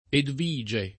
vai all'elenco alfabetico delle voci ingrandisci il carattere 100% rimpicciolisci il carattere stampa invia tramite posta elettronica codividi su Facebook Edvige [ edv &J e ] (antiq. Eduvige [ eduv &J e ]) pers. f.